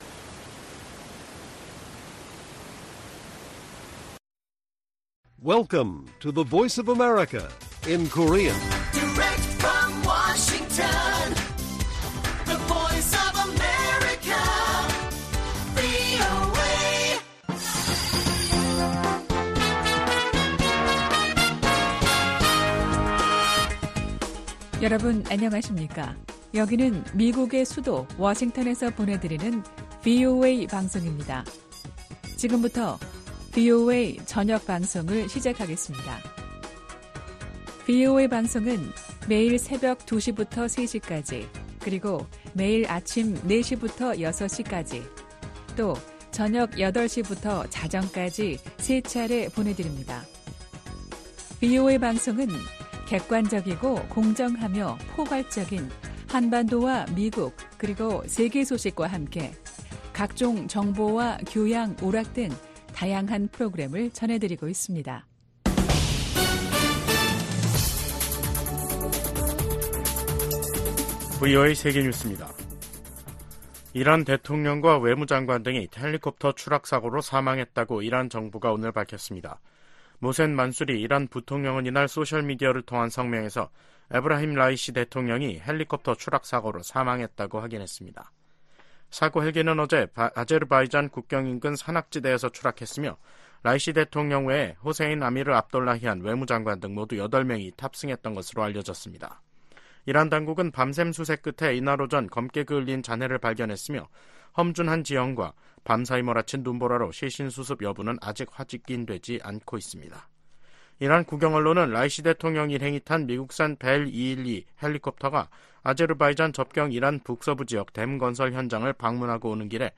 VOA 한국어 간판 뉴스 프로그램 '뉴스 투데이', 2024년 5월 20일 1부 방송입니다. 미국 국무부가 북한의 단거리 탄도미사일 발사를 규탄하며 거듭되는 북한 미사일 발사의 불법성을 지적했습니다. 북한과 러시아가 대량살상무기 관련 불법 금융활동 분야에서 가장 큰 위협국이라고 미국 재무부가 밝혔습니다. 미국, 한국, 일본의 협력 강화를 독려하는 결의안이 미국 하원 외교위원회를 통과했습니다.